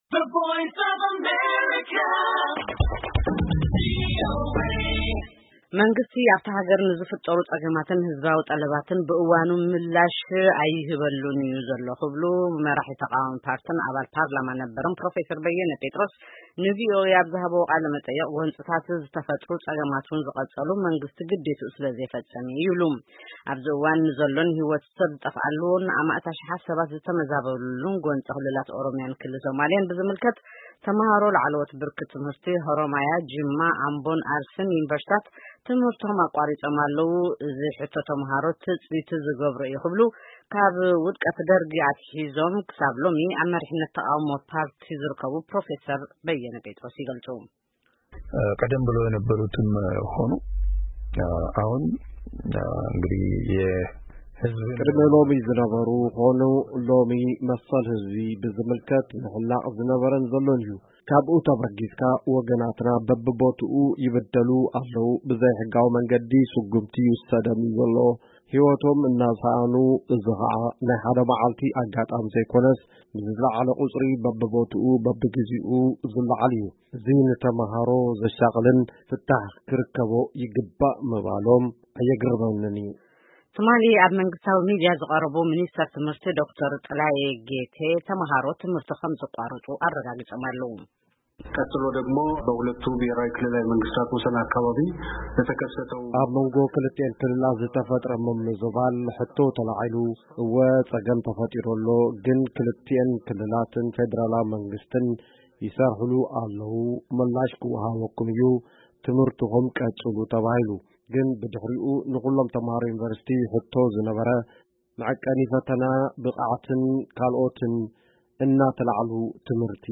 መንግስቲ ኣብታ ሃገር ንዝፈጠሩ ጸገማትን ህዝባዊ ጠለባትን ብእዋኑ ምላሽ ኣይህበሉን’ዩ ክብሉ መራሒ ተቓዋሚ ፓርቲን አባል ፓርላማ ነበር ፕሮፌሰር በየን ጴጥሮስ ንድምጺ ኣሜሪካ ኣብ ዝሃብዎ ቃለ መጠይቕ ገሊጾም። ንሶም ብተወሳኺ ጎንጽታት ዝተፈጥሩ ጸገማት’ውን ዝቐጸሉ መንግስቲ ግዲኡ ስለ ዘይፈጸመ’ዩ ይብሉ። ኣብዚ እዋን ንዘሎ ህይወት ሰብ ዝጠፍአሉ አማኢት አሽሓት ሰባት ዝተመዛበሉ ጎንጺ ክልላት ኦሮምያን ክልል ሶማሌን ብዝምልከት ተማሃሮ ላዕለዋት ደረጃ ትምህርቲ...